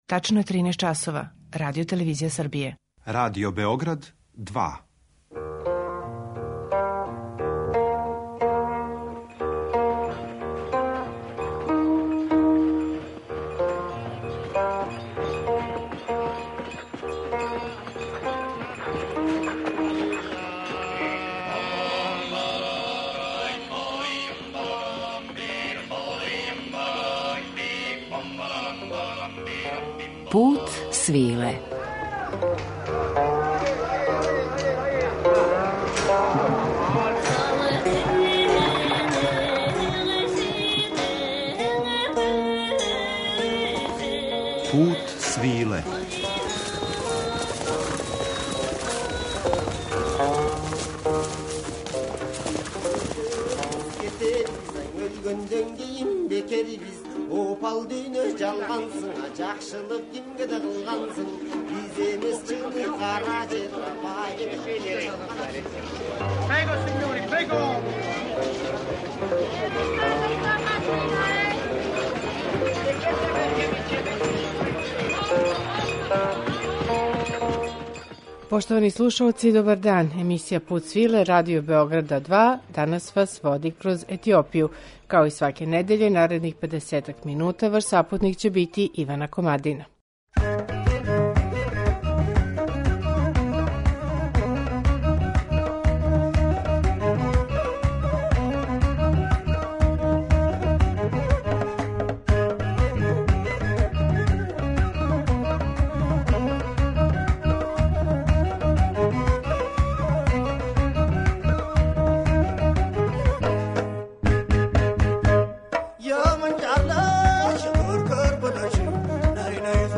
Ovu, kao i mnoge druge neobične strane Etiopije, osvetlićemo u današnjem Putu svile , u muzičkom društvu dve grupe iz Adis Abebe, Ethiocolor i Fendika , koje na savremen način neguju muzičku tradiciju etiopskih pesnika - pevača, unoseći elemente tradicionalne kulture iz različitih delova svoje zemlje: načine pevanja, instrumente, ritmove kao što su volo, gondar, oromo.